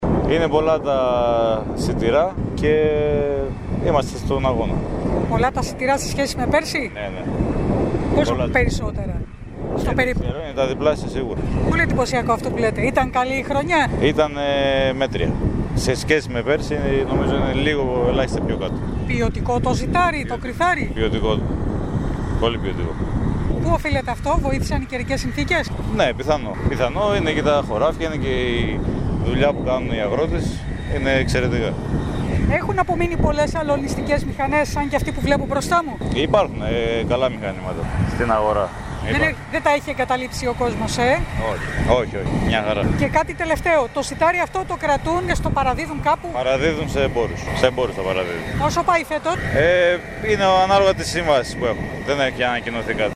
Τον συναντήσαμε στο πεδίο της «μάχης» την ώρα του αλωνισμού ενός ακόμη αγροτεμαχίου στην ΠΕ Ροδόπης.
Ροδόπη: Μέρες του θέρουςΦέτος, έχουν σπαρεί με σιτηρά και κριθάρι τα διπλάσια στρέμματα σε σχέση με πέρσι. «Η απόδοση κρίνεται μέτρια ποσοτικά, η ποιότητα όμως είναι εξαιρετική» σημείωσε μιλώντας στην ΕΡΤ Κομοτηνής και στην εκπομπή «Καθημερινές Ιστορίες» (5-7-2021) .